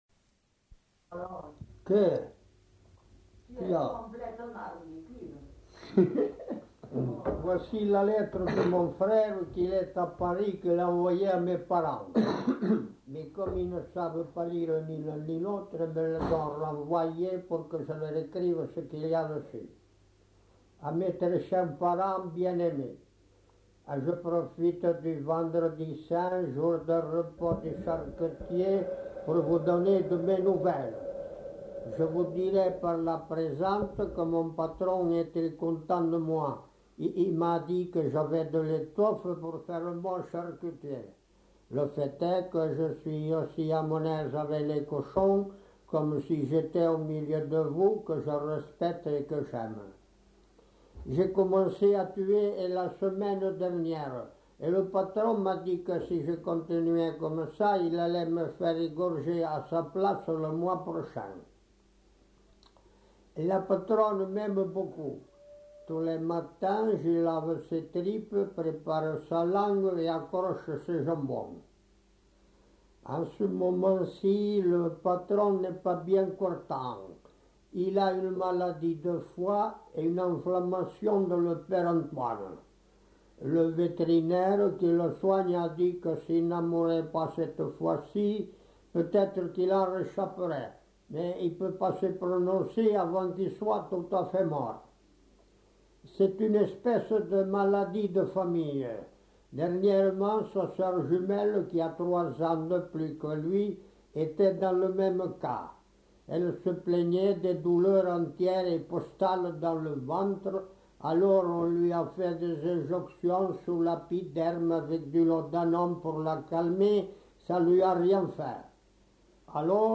Aire culturelle : Savès
Lieu : Cazaux-Savès
Genre : conte-légende-récit
Ecouter-voir : archives sonores en ligne